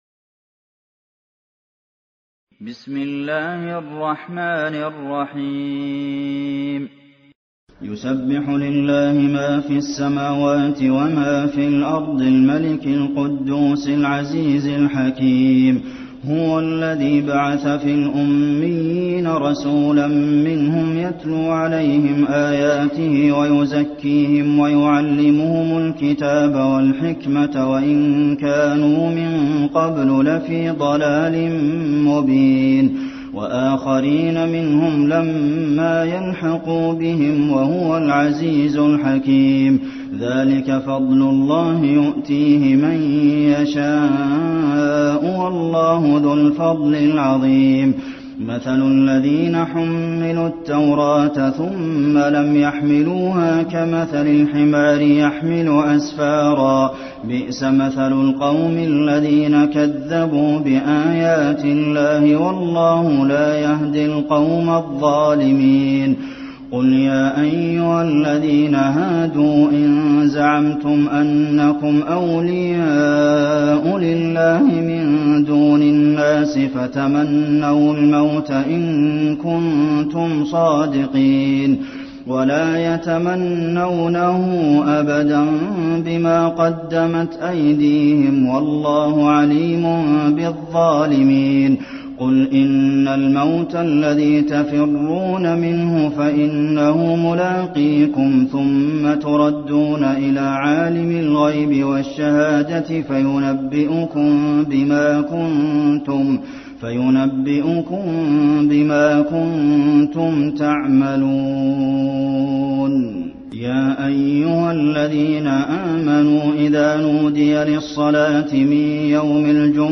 المكان: المسجد النبوي الجمعة